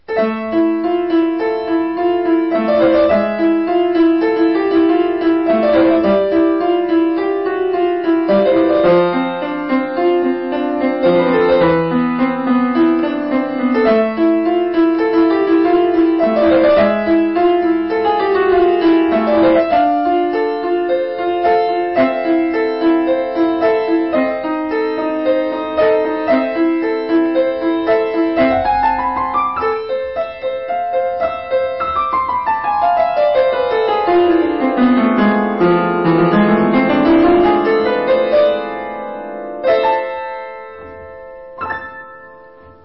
אהבתי את המשחקים על המינור מאז'ור....